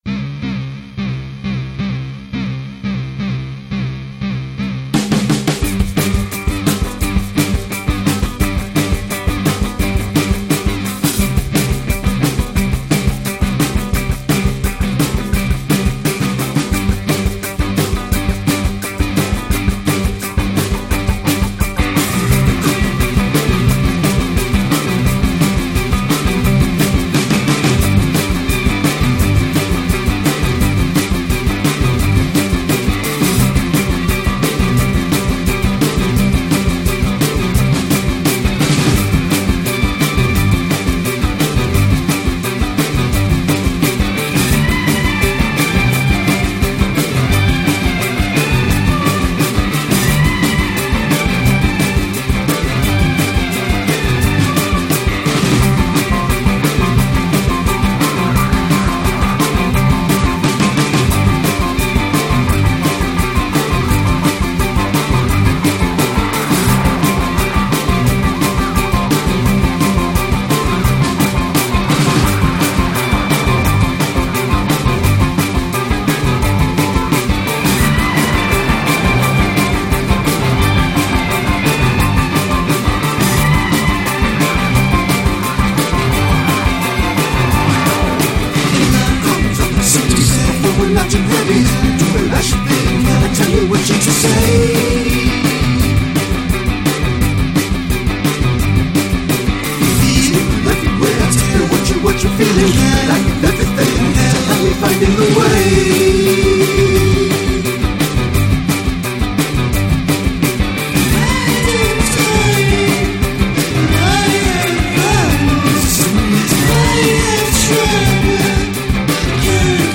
Chicago analog synth psych-pop outfit